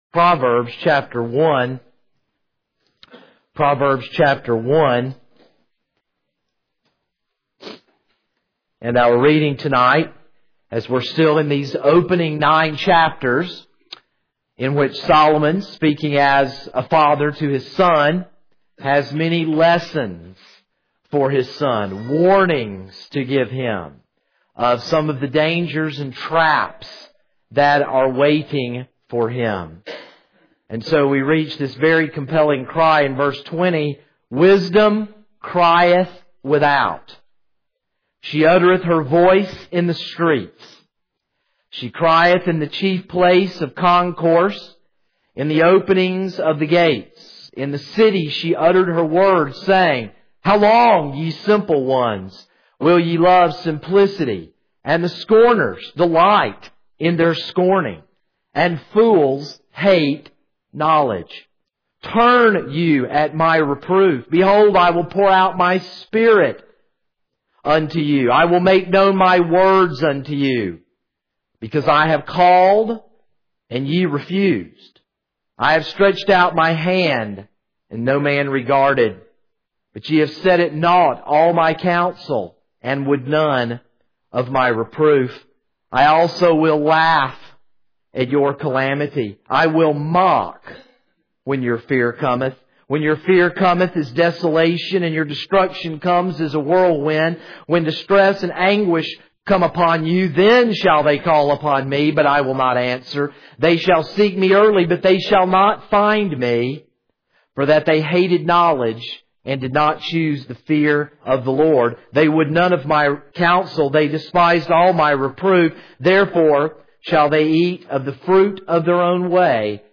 This is a sermon on Proverbs 1:20-33.